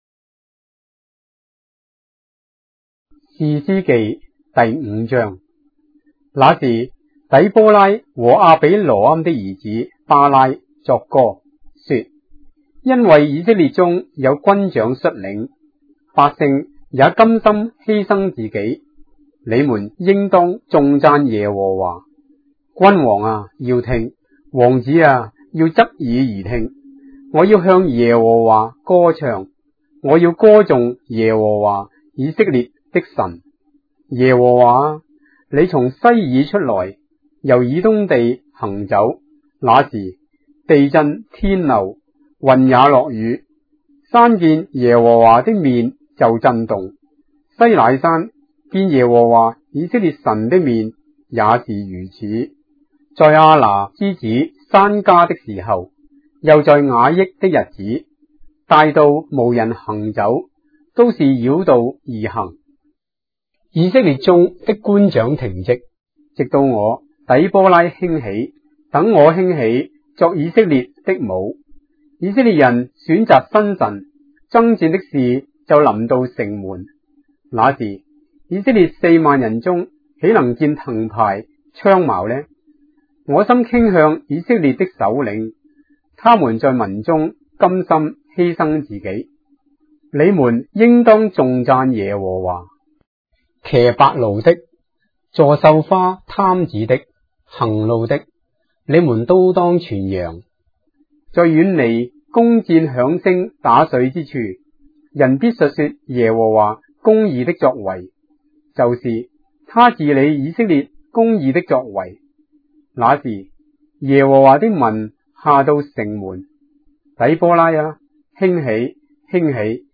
章的聖經在中國的語言，音頻旁白- Judges, chapter 5 of the Holy Bible in Traditional Chinese